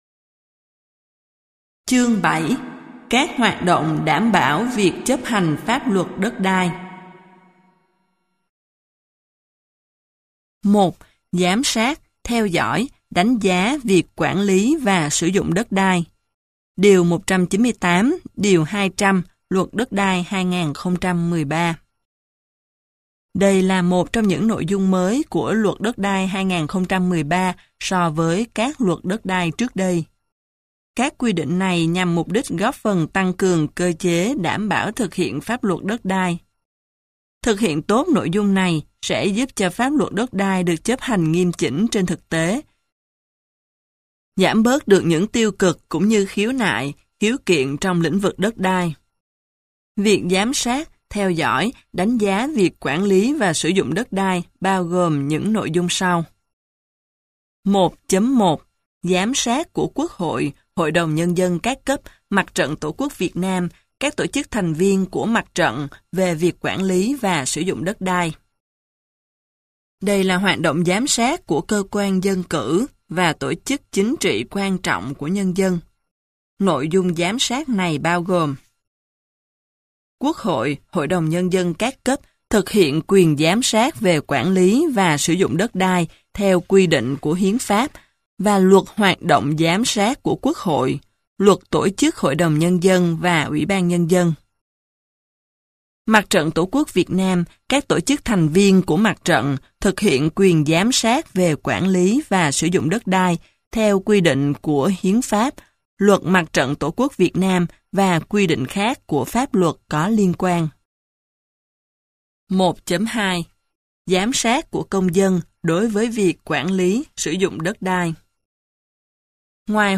Sách nói Giáo Trình Luật Đất Đai - TS Lưu Quốc Thái - Sách Nói Online Hay
Giáo Trình Luật Đất Đai Tác giả: TS Lưu Quốc Thái Nhà xuất bản Hông Đức Giọng đọc: nhiều người đọc